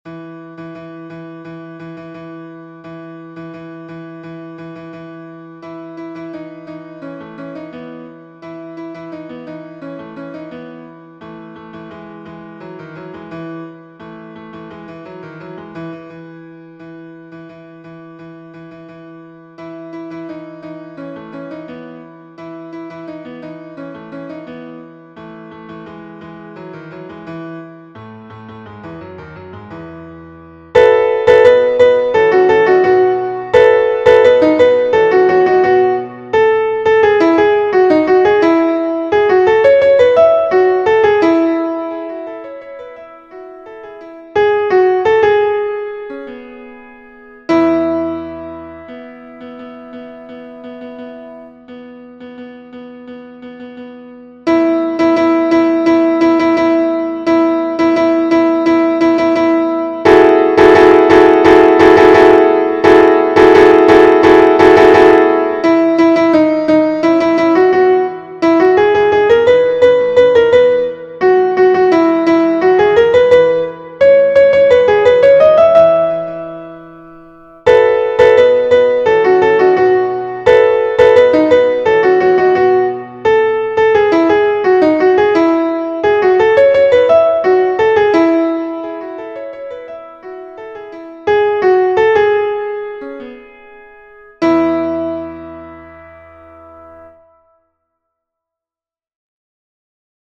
- Chant pour 4 voix mixtes SATB
MP3 versions piano
Alto Version Piano